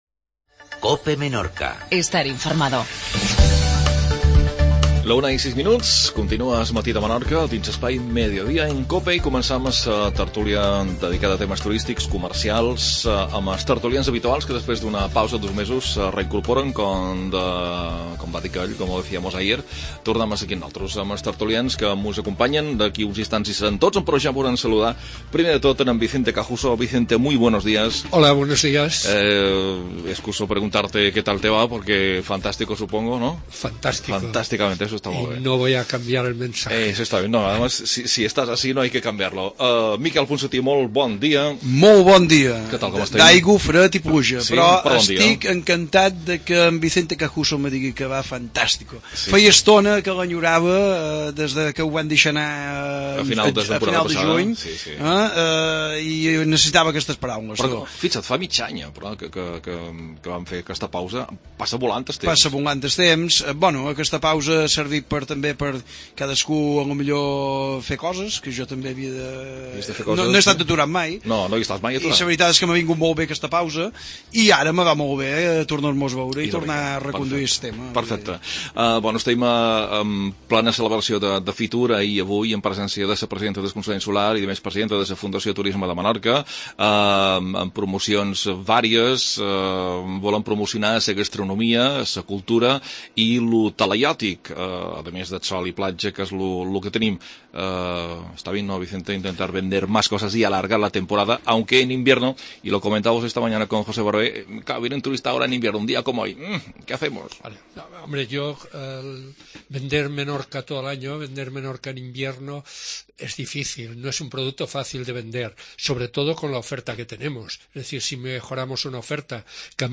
Tertulia dels dijous